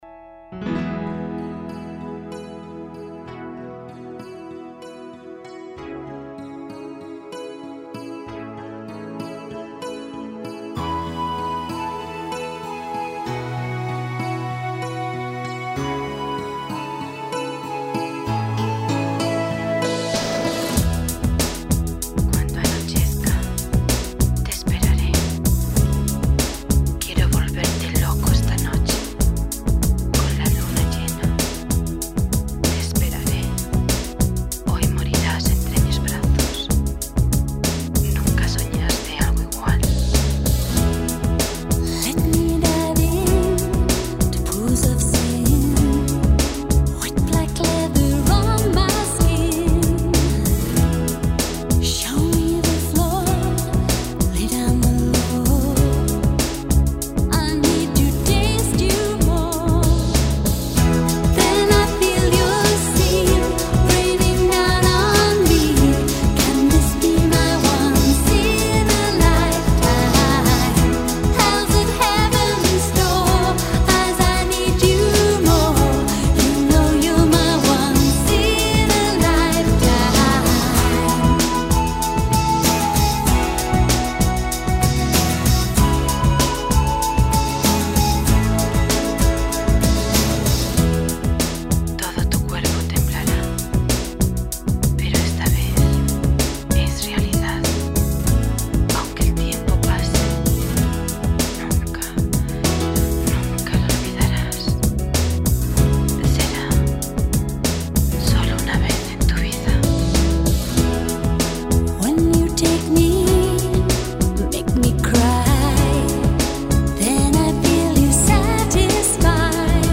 整 张专辑没有丝毫古典音乐的踪影